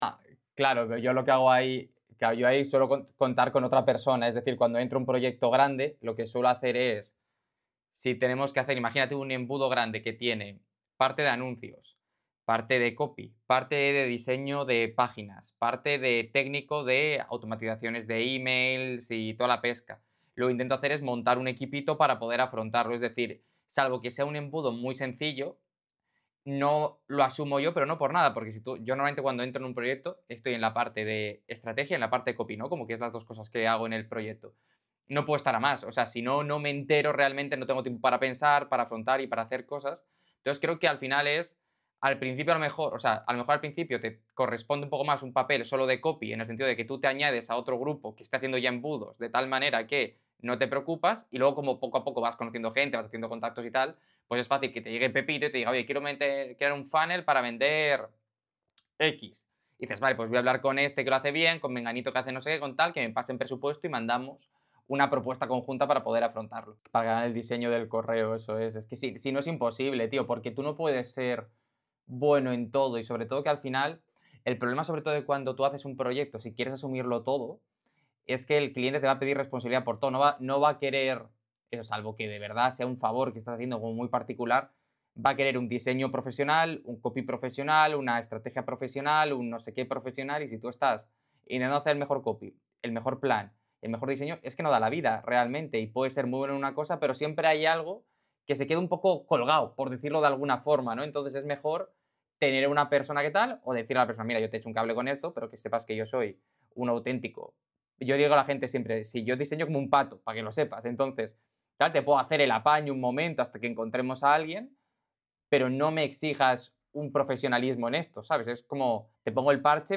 Copywriter senior charla con copywriter novato